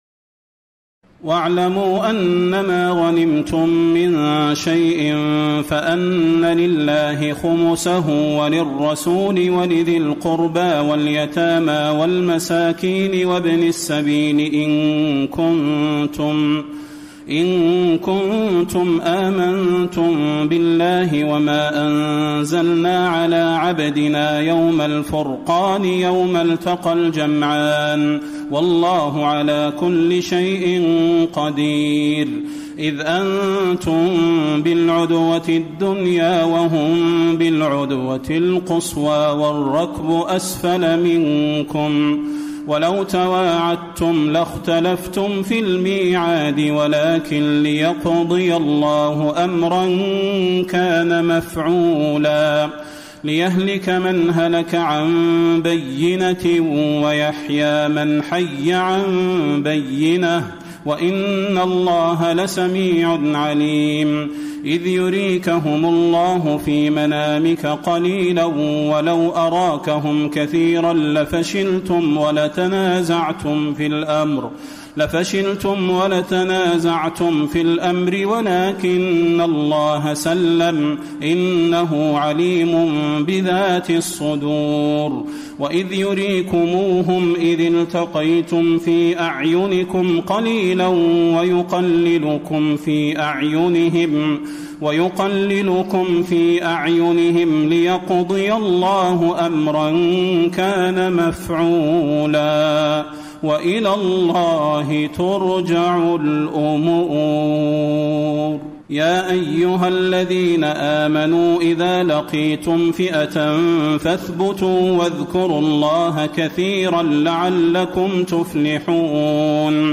تراويح الليلة العاشرة رمضان 1434هـ من سورتي الأنفال (41-75) و التوبة (1-37) Taraweeh 10 st night Ramadan 1434H from Surah Al-Anfal and At-Tawba > تراويح الحرم النبوي عام 1434 🕌 > التراويح - تلاوات الحرمين